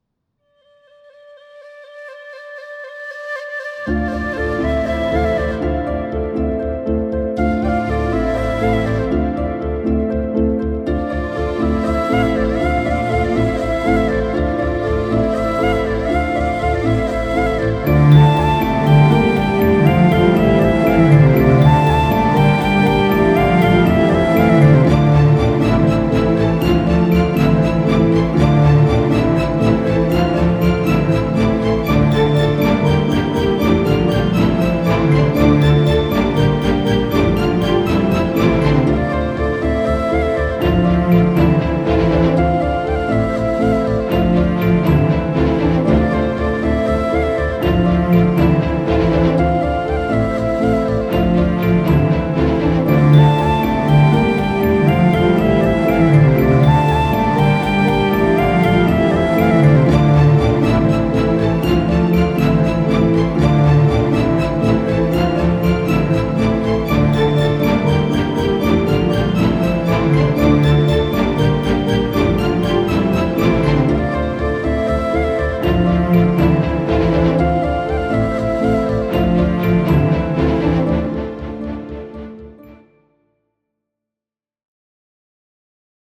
without dialogues and disturbances